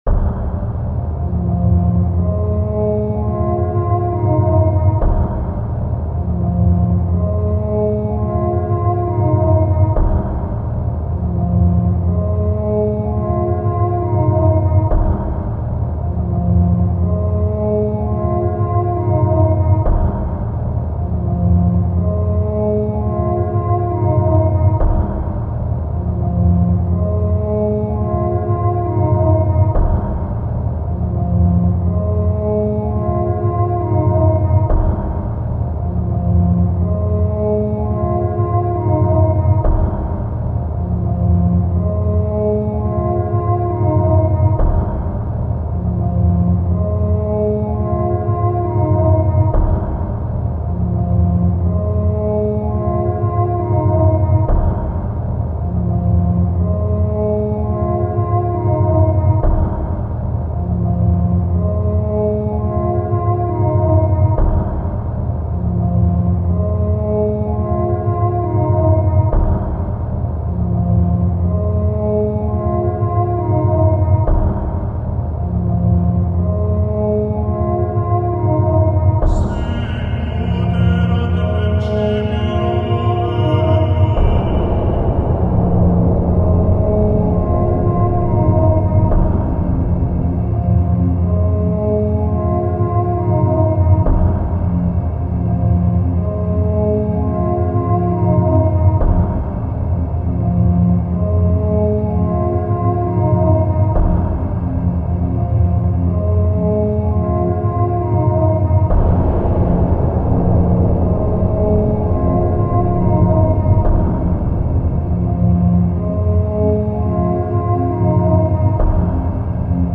weird music about industrial�s delights